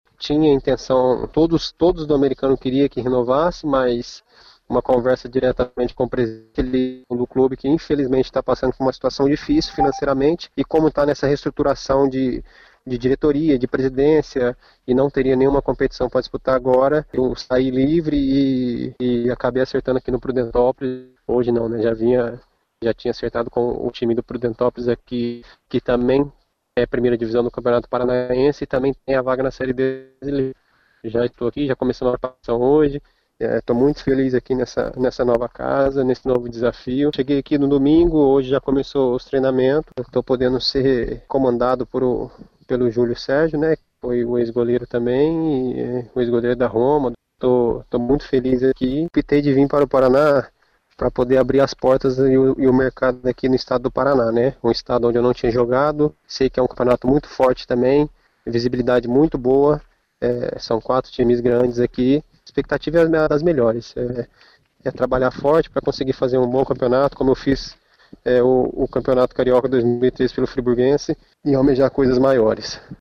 Em entrevista exclusiva a Jornada Interativa,